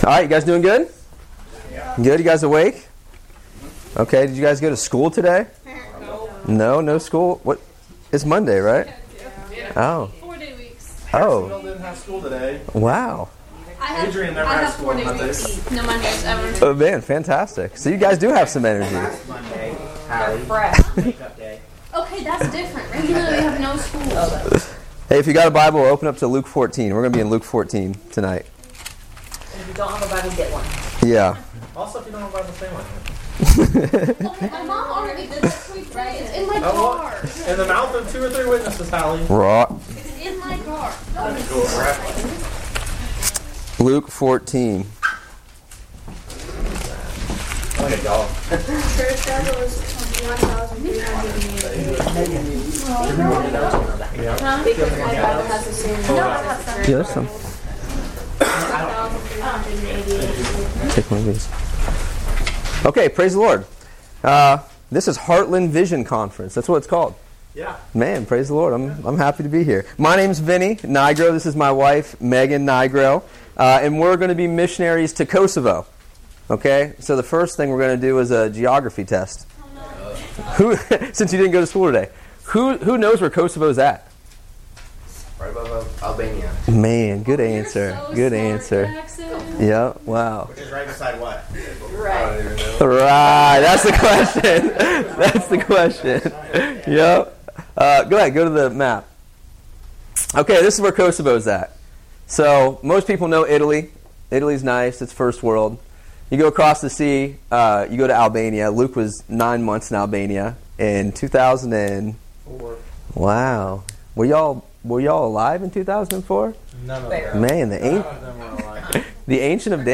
Every year, on Monday and Tuesday nights, during the Vision Conference.
This is a great time of followship, with a more laidback environment, giving the youth the chance to ask questions to the missionaries; about the mission field and how they’re following the call to "GO!" (Matt 28:19). Also, hearing personal testimonies and experiences from the missionaries.